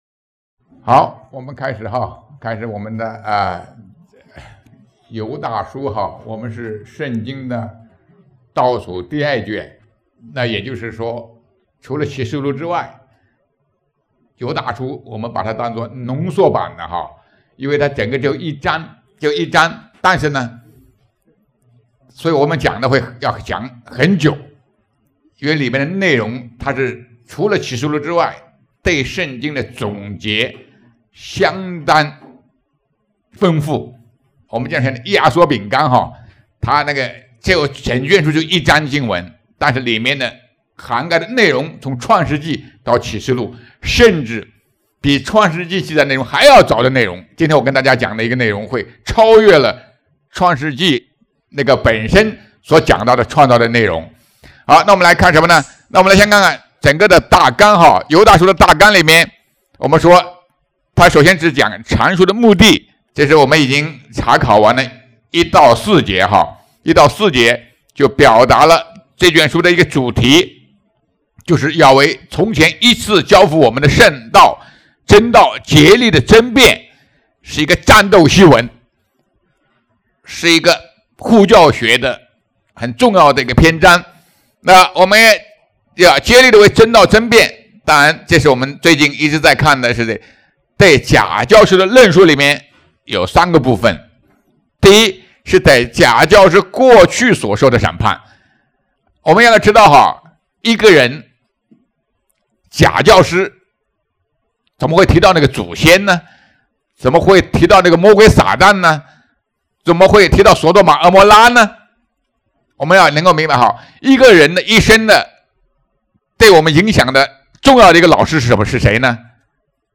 2024年12月1日 下午4:43 作者：admin 分类： 犹大书圣经讲道 阅读(1.73K